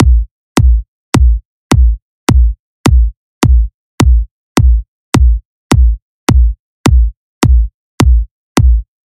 105 bpm th drum loop kick
描述：这些循环是在FL Studio 12中创建的，分为4个部分：踢腿、拍子、hihat、perc和snare。我认为，这些循环可以用于热带屋和舞厅。
Tag: 105 bpm House Loops Drum Loops 1.54 MB wav Key : Unknown